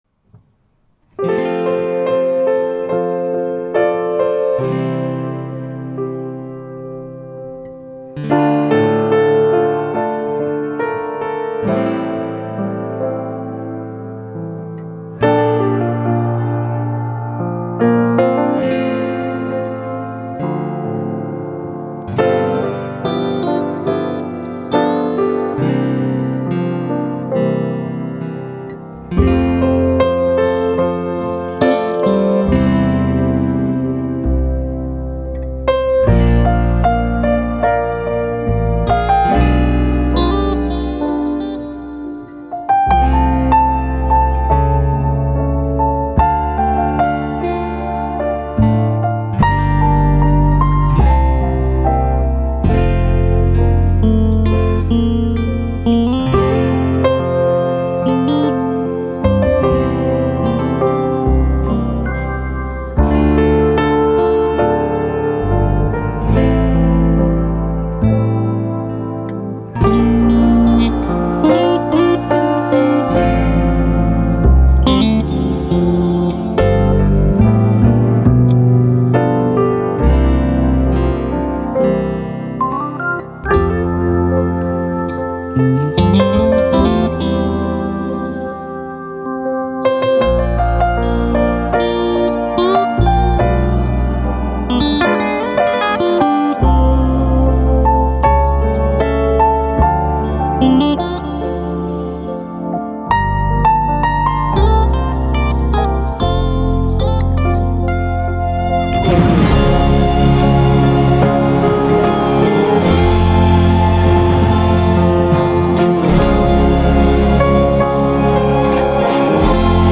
Gitarer, bass (siste del), effekter og mix
flygel (Kirka i Stallen), pålegg av bass (første del) og orgel